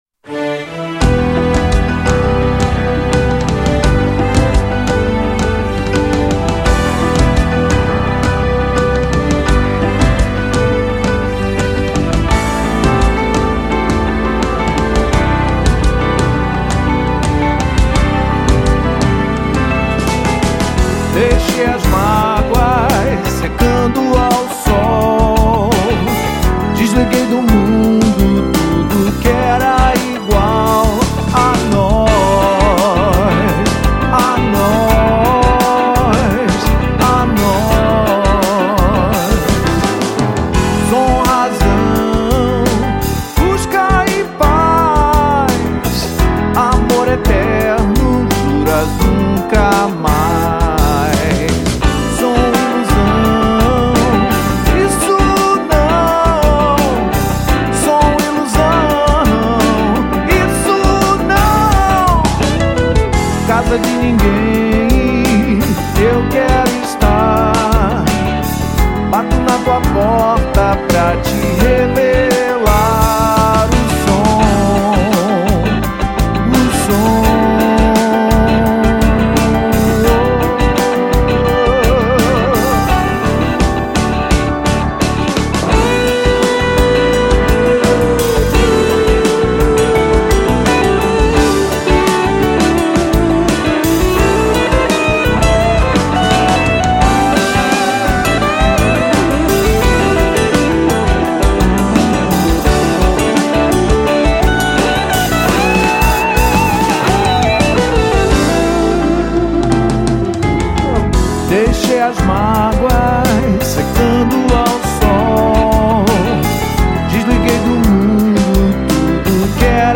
2317   03:28:00   Faixa:     Rock Nacional